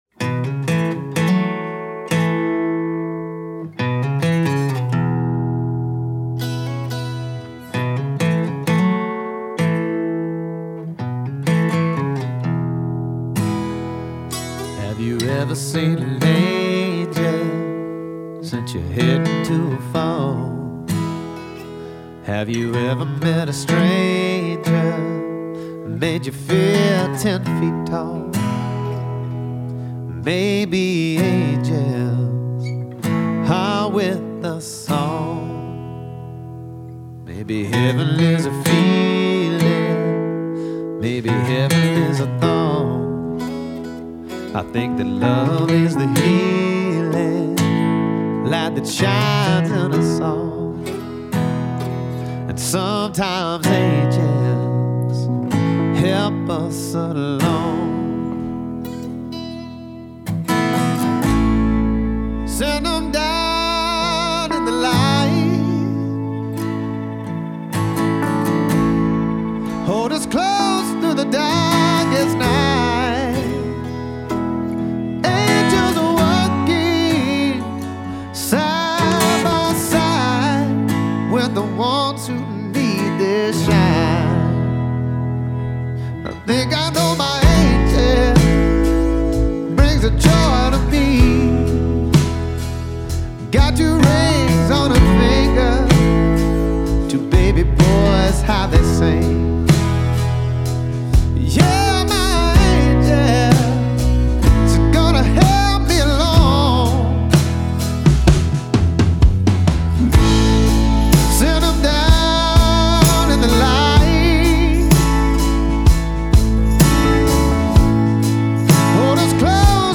bass
keys
drums